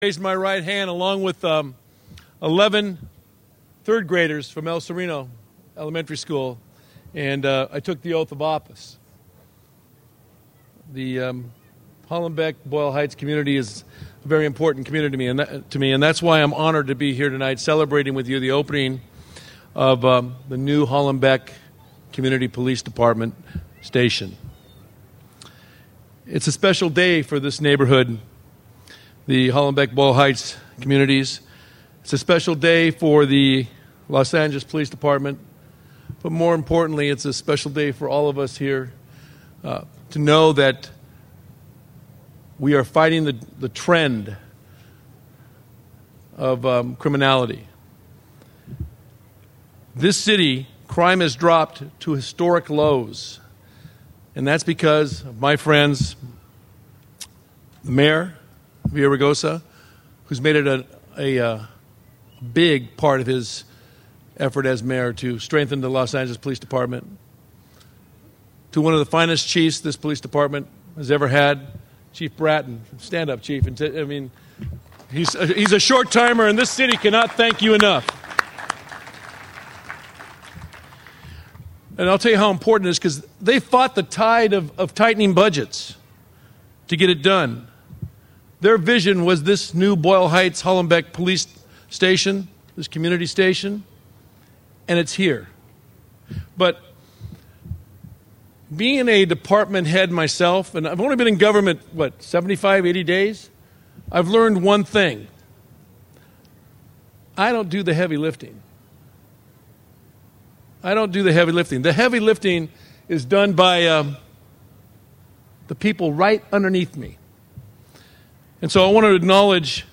City Attorney Trutanich - podcast